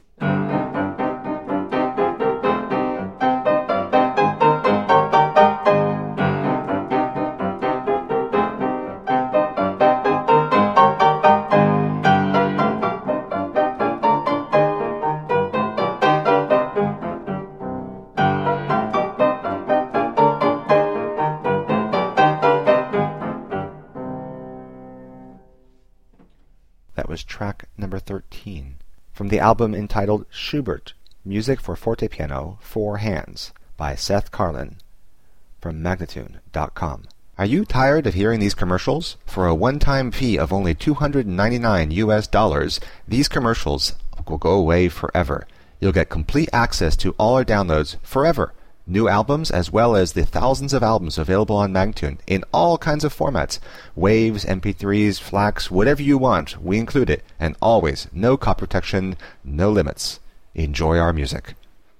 Fortepiano music from the early 1800s.